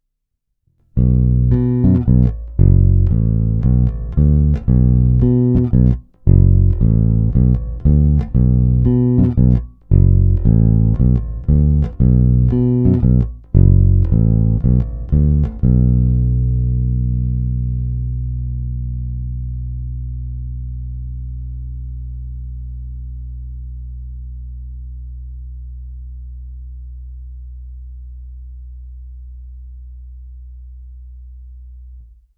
V následujících nahrávkách jsem malinko přidal basy (cca o 1/4), výšky přidávat ani ubírat nebylo třeba.
Ukázky jsou nahrány rovnou do zvukové karty a jen normalizovány. Hráno vždy nad aktivním snímačem, v případě obou pak mezi nimi.
Snímač u krku